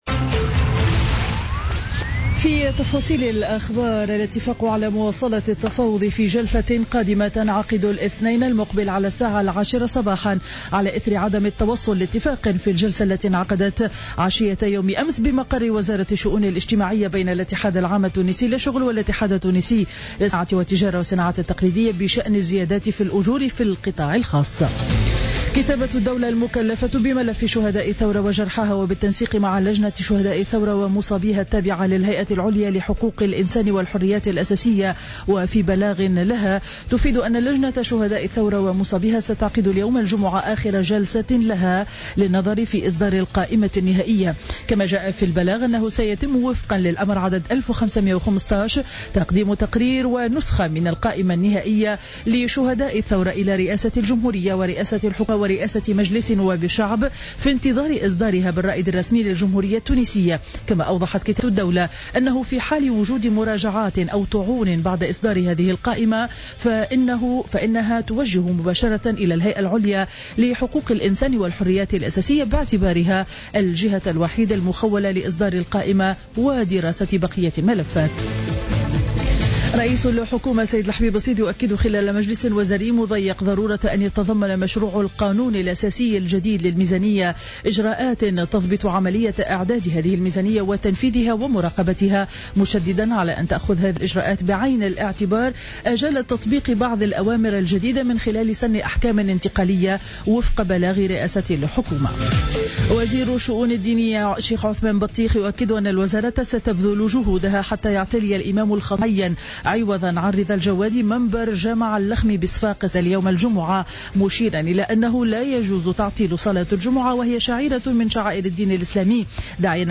نشرة أخبار السابعة صباحا ليوم الجمعة 30 أكتوبر 2015